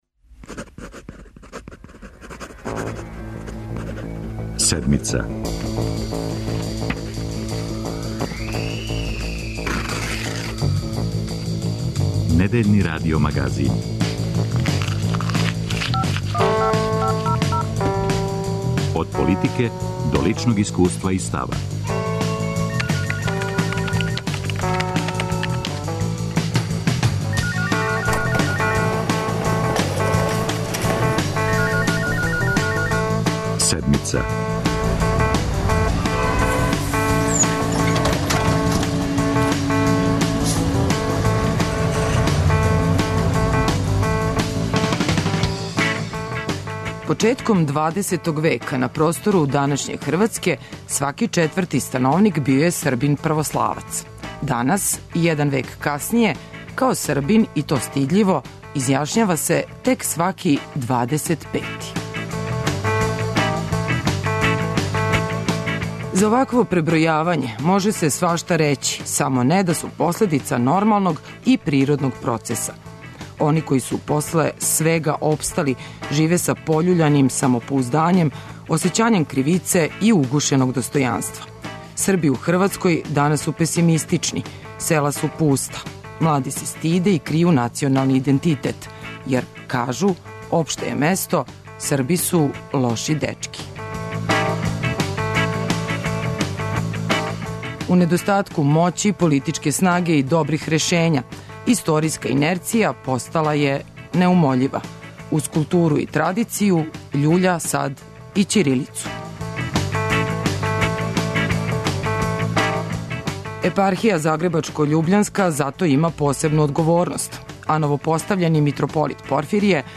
О изазовима живота Срба у Хрватској, повратку избеглих и опстанку ћирилице за Седмицу говори нови митрополит загребачко-љубљански Порфирије.